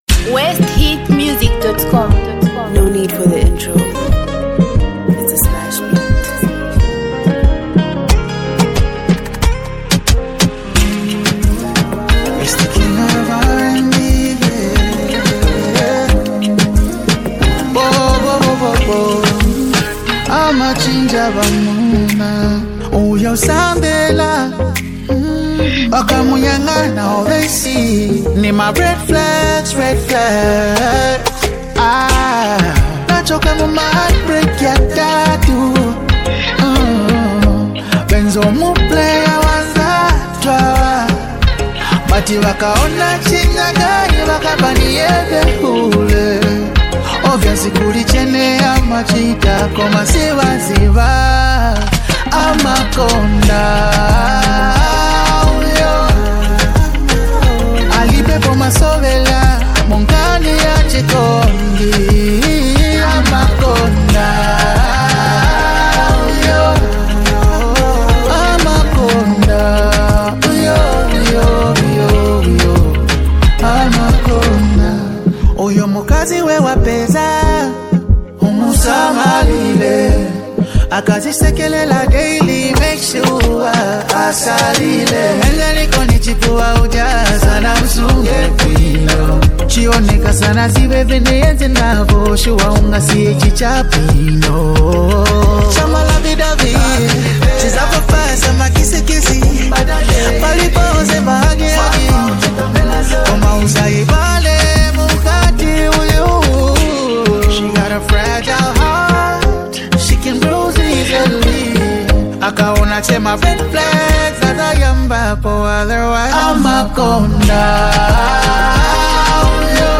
Zambia Music